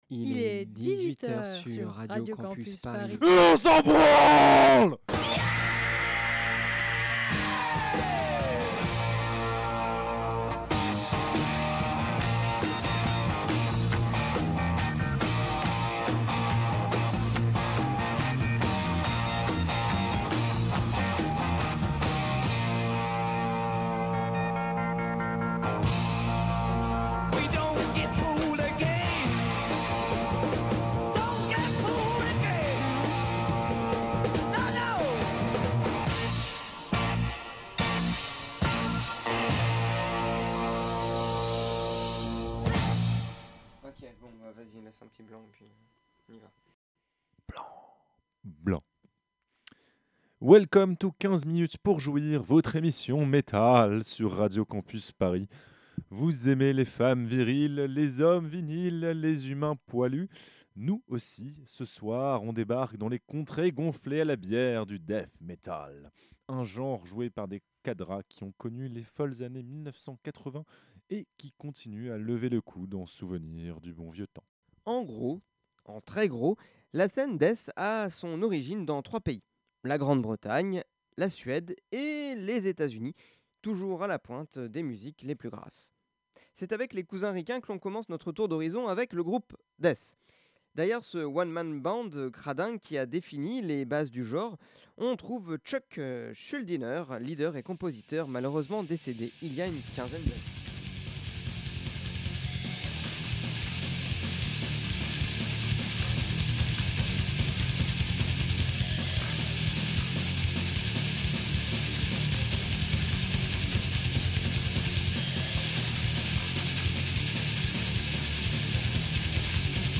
Huitième date avec toi, à l'arrache car à l'image du genre qu'on va partager ensemble : le Death Metal. Déviant, malsain, brutal, le Death pousse les curseurs du Thrash au max pour un coït express et bourrin qui vous fera éjaculer des oreilles avec le plaisir des coups rapides entre deux camions citernes, une nuit trop arrosée quelque part dans le sud poisseux des Etats-Unis ou dans l'obscurité miteuse d'une ville scandinave...